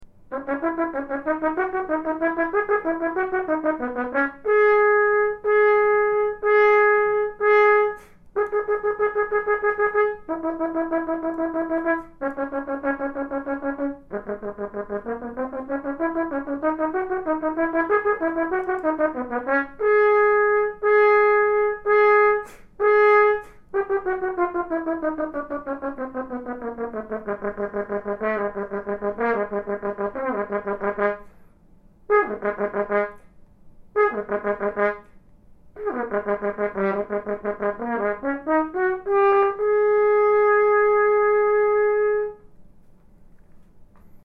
Comparing Straight Mutes
Think all horn straight mutes sound the same?
As an experiment, I recently recorded a muted passage from Jan Koetsier’s Romanza, Op. 59, No. 2, using four different mutes.
Trumcor Model #45: I have two of these mutes, a relatively new one, and a much older (though still functioning) one.